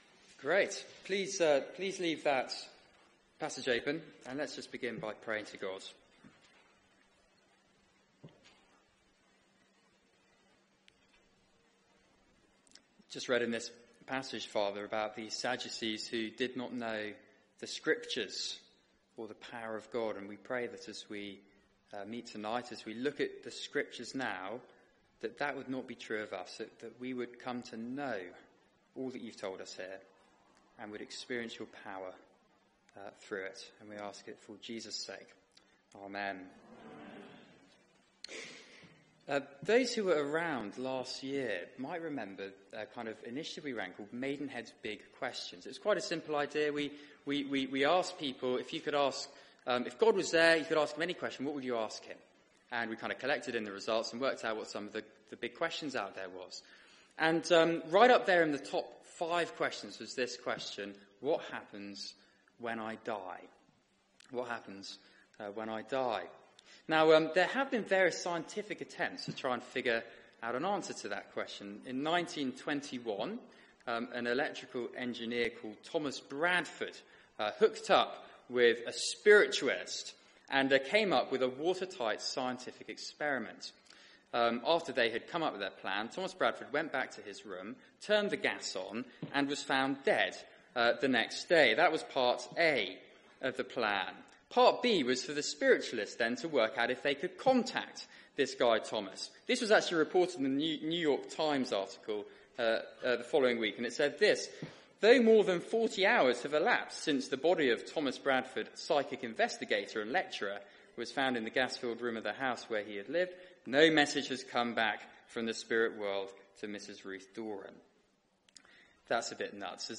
Media for 6:30pm Service on Sun 28th May 2017 18:30 Speaker
Mark 12:18-27 Series: Questioning the King Theme: Is eternal life for real? Sermon Search the media library There are recordings here going back several years.